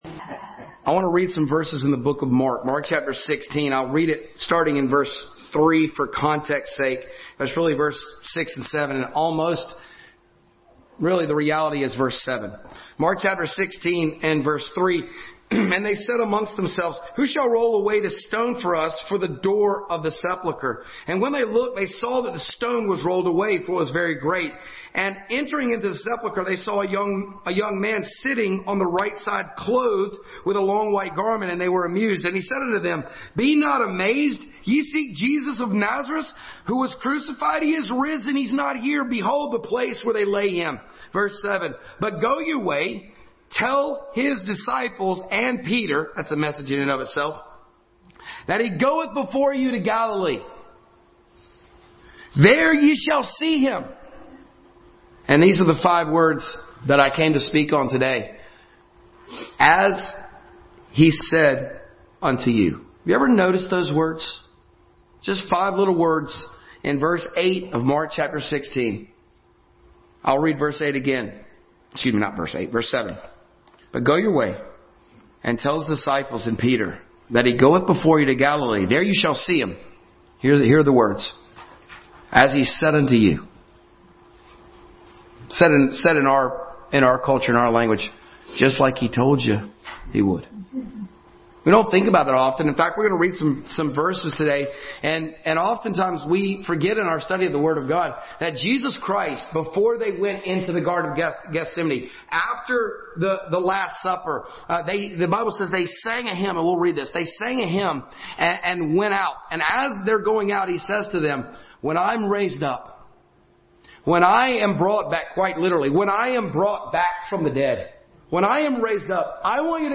As He Said Unto You Passage: Mark 16:3-7, 4:35-41, 14:26-30, Matthew 26:30-35 Service Type: Family Bible Hour